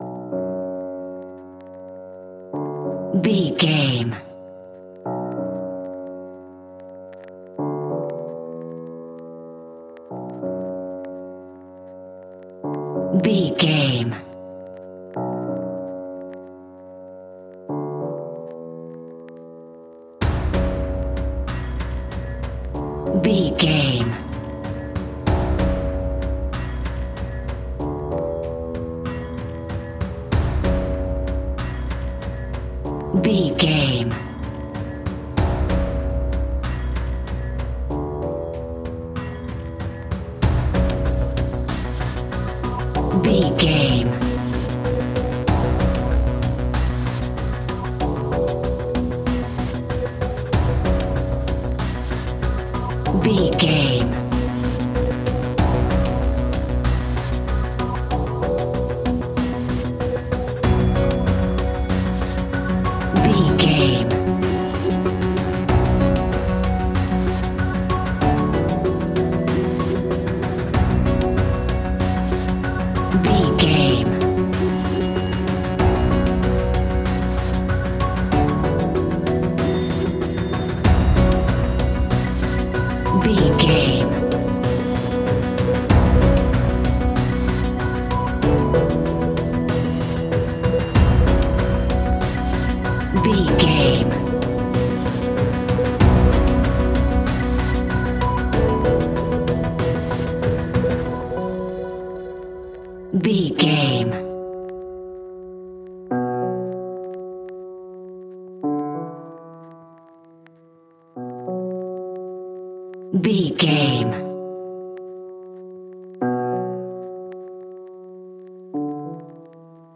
In-crescendo
Thriller
Aeolian/Minor
scary
ominous
dark
eerie
electronic music
electronic instrumentals
Horror Pads
Horror Synths